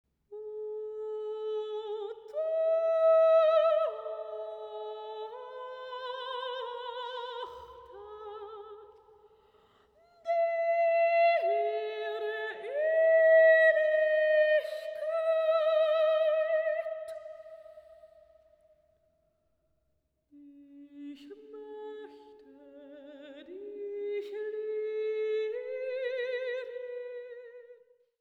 Neue Musik
Vokalmusik
Solostimme(n)